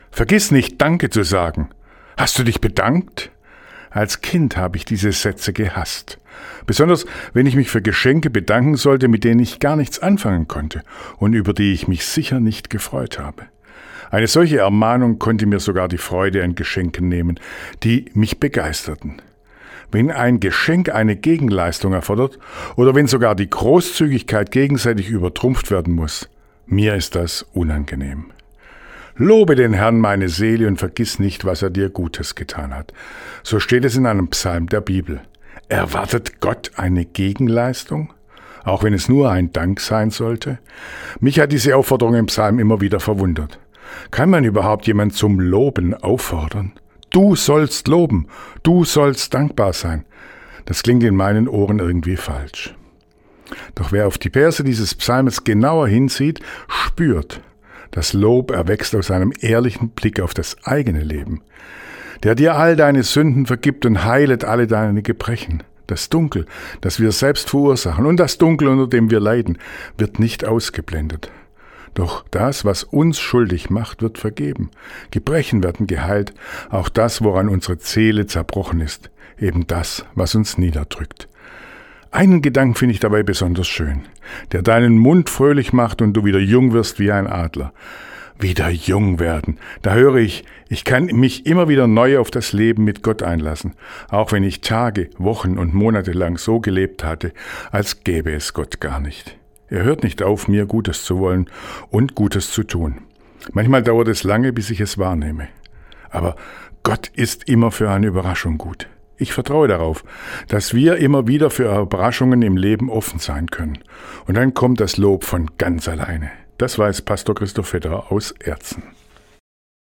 Radioandacht vom 22. September